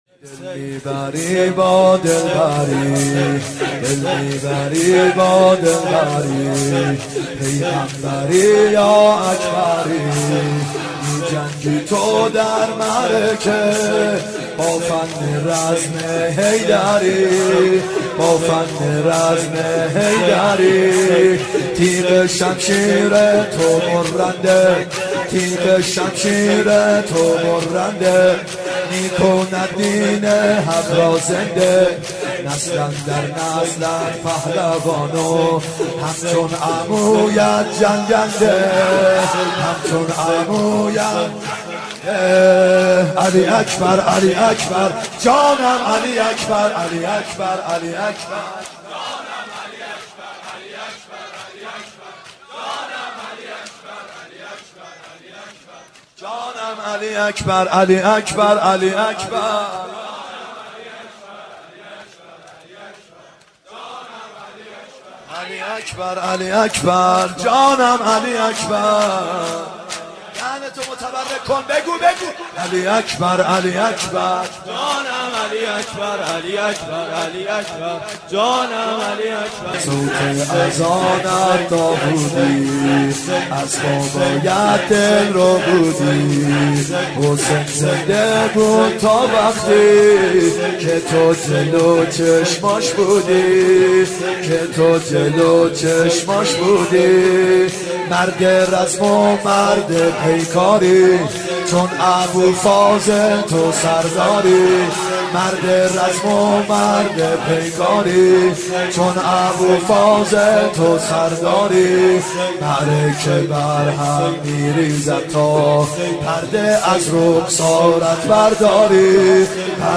محرم1 9شب هشتم شور ( دل میبری یا که دلبری پیغمبری یا که اکبری
محرم 91 ( هیأت یامهدی عج)